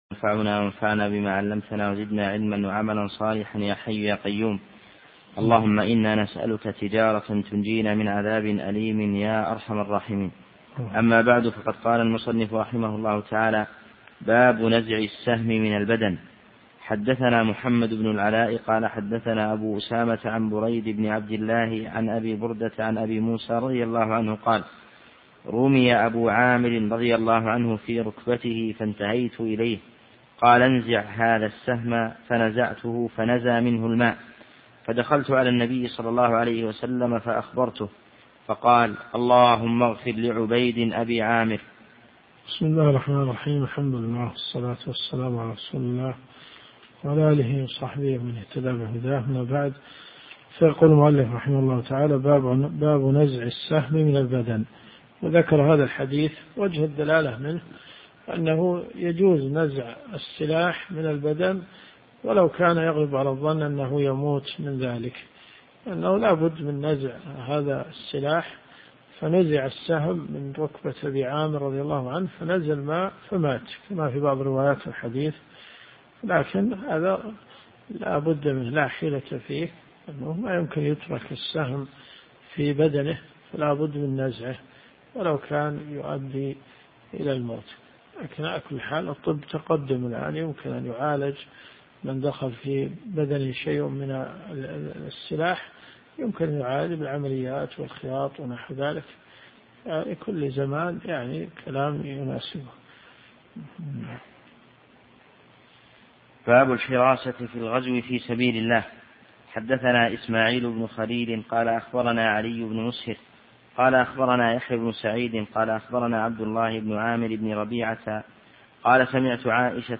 الرئيسية الكتب المسموعة [ قسم الحديث ] > صحيح البخاري .